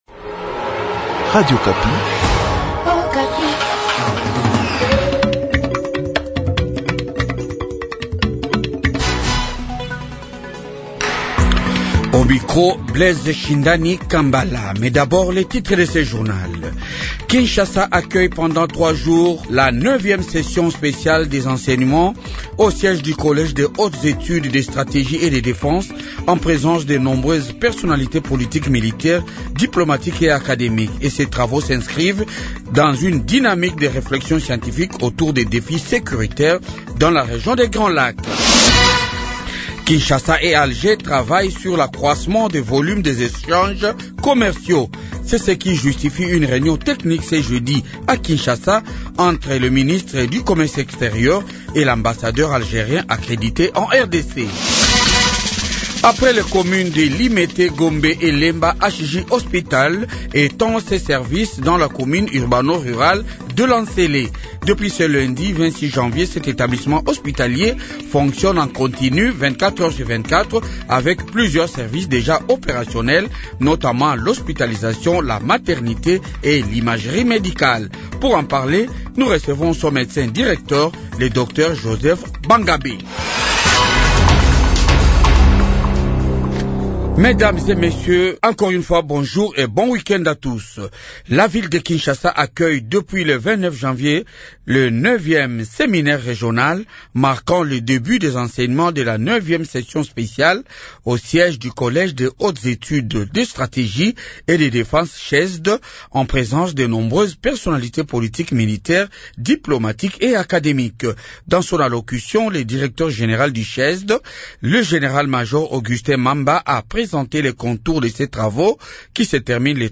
Journal du matin 7h